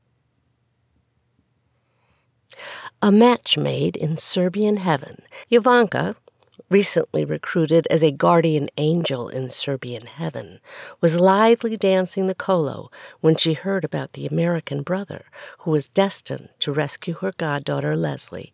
There’s still a tiny bit of motor noise in there.
The first one is plain mastering and very gentle noise reduction.